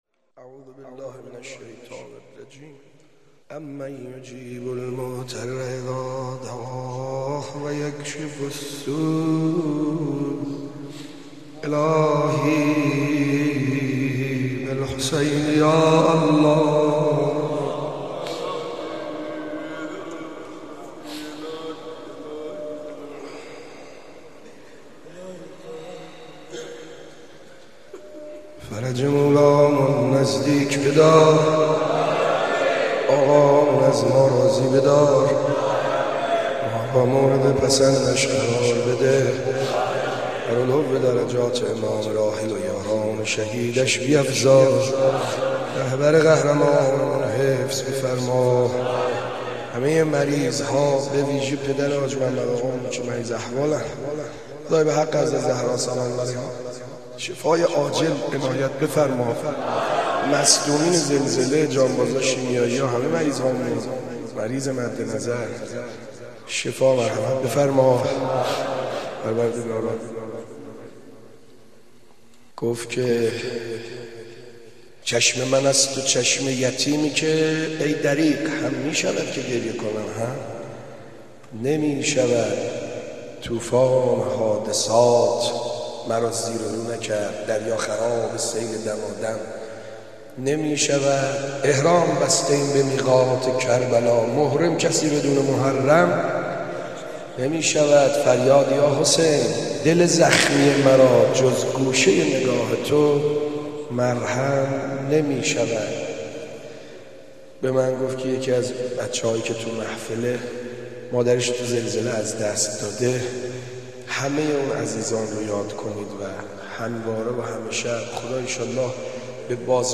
دعای کمیل